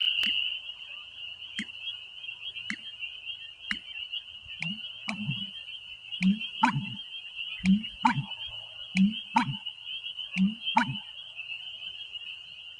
大麻鳽叫声
大麻鳽叫声低沉似牛叫 大围山国家森林公园浏阳河源头鸟鸣声